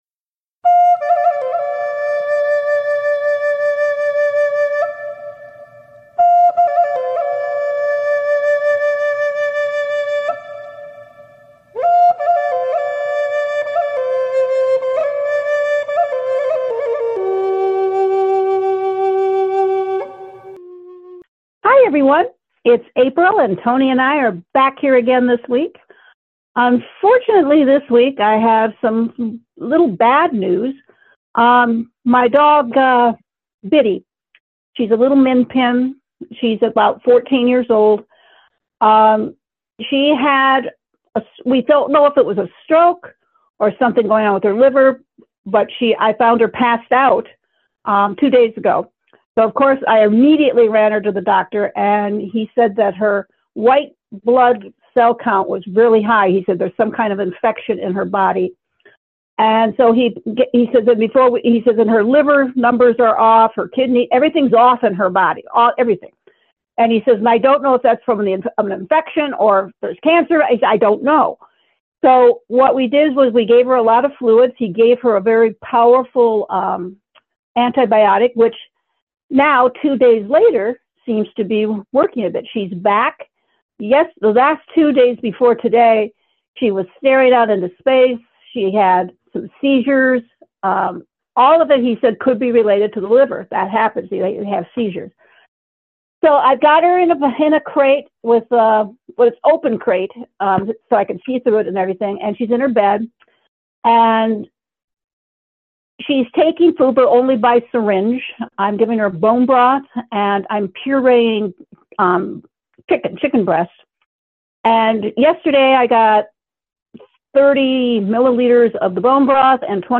Today's best talk radio shows from the hosts on AMFM 247 Broadcasting Network on demand 24/7 … continue reading 352 episodes # Business # Government # Politics # Talk # AMFM247 Broadcasting # News # AMFM # Broadcast